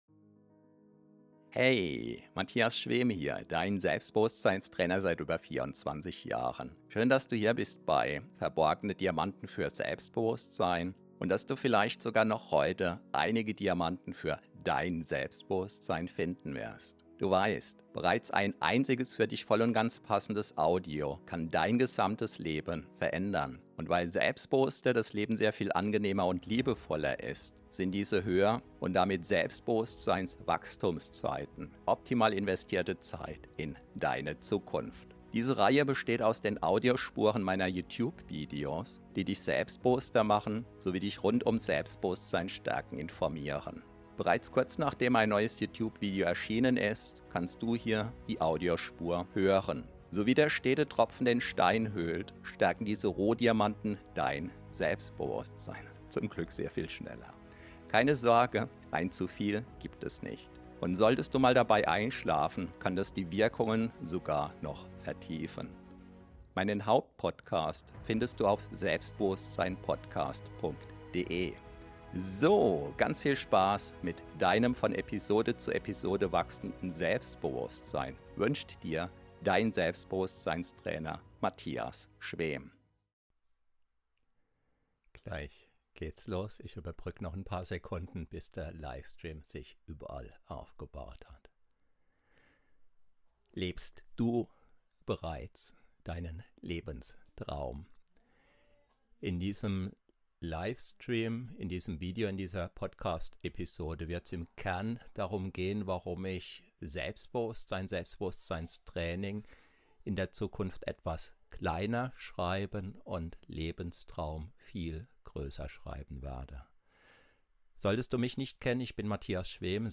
Beschreibung vor 2 Jahren Willkommen zu einem ganz besonderen Live-Video! Heute markiert nicht nur das Ende einer Ära, sondern auch den aufregenden Start eines neuen Kapitels.